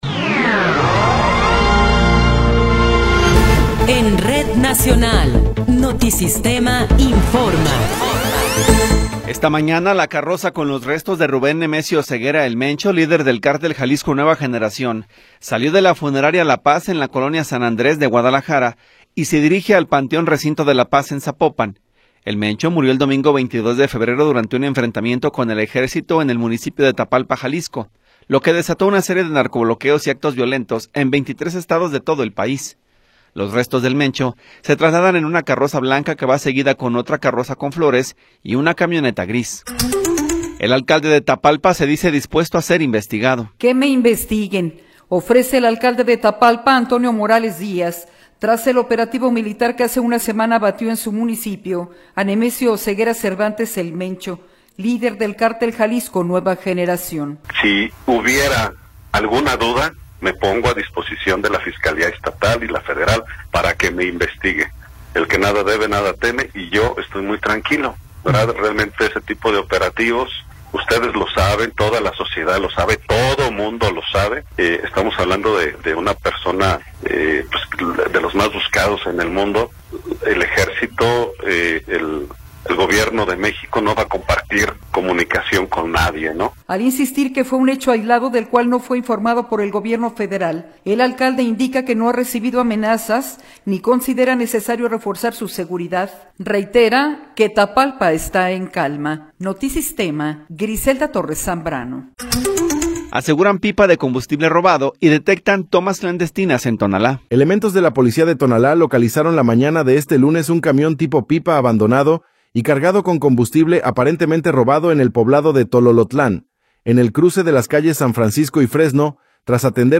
Noticiero 13 hrs. – 2 de Marzo de 2026